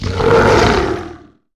Cri d'Ursaking, « Lune Vermeille » dans Pokémon HOME.
Cri_0901_Lune_Vermeille_HOME.ogg